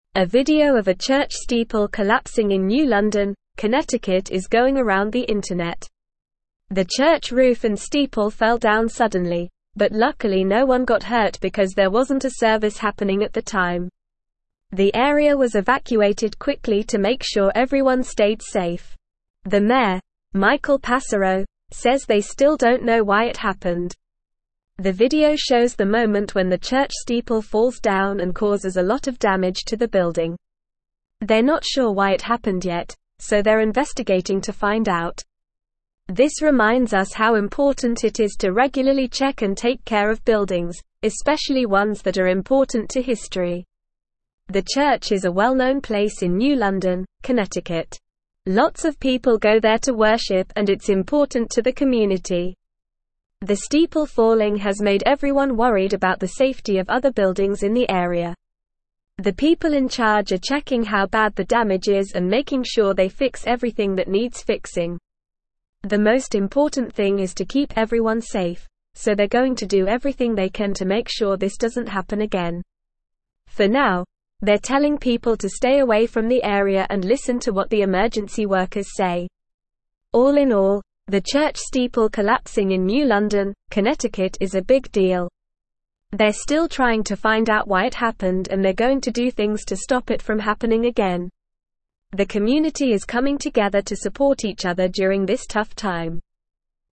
Normal
English-Newsroom-Upper-Intermediate-NORMAL-Reading-Church-roof-and-steeple-collapse-in-New-London.mp3